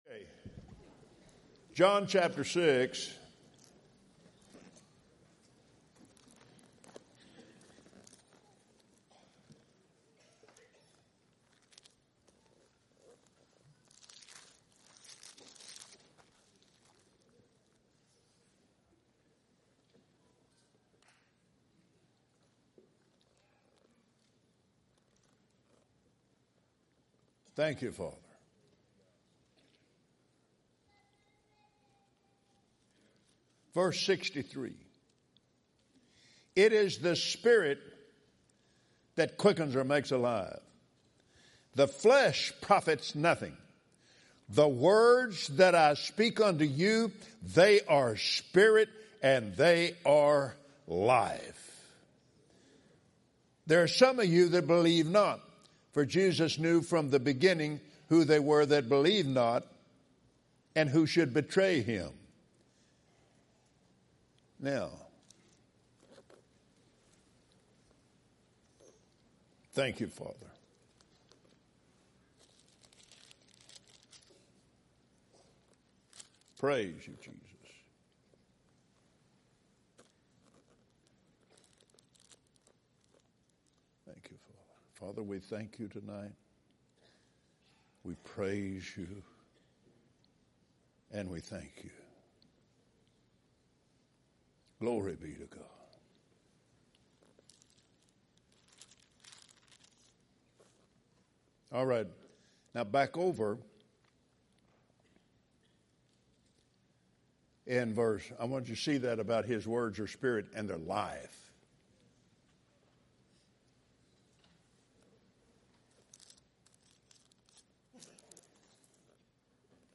Branson Victory Campaign | Listen For the Voice of the Lord – Offering Message - Kenneth Copeland | Fri., 7 p.m.